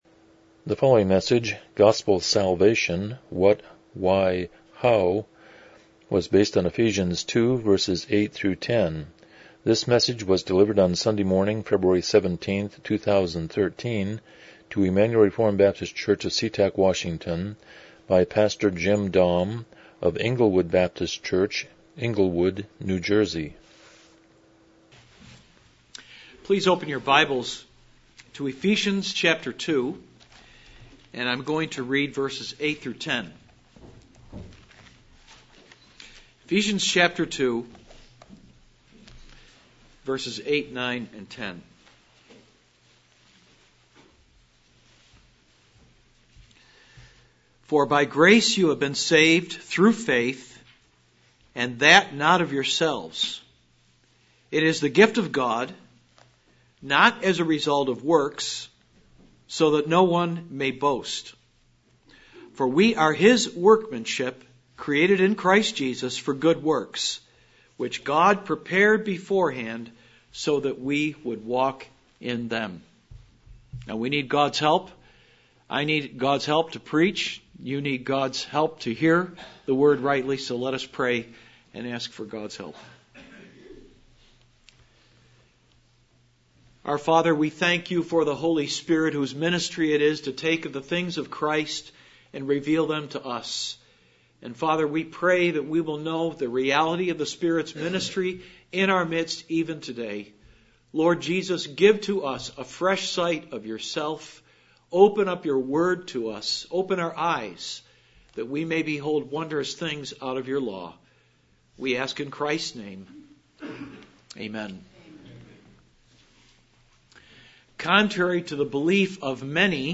Ephesians 2:8-10 Service Type: Morning Worship « 20 Proverbs 6:12 25 The Sermon on the Mount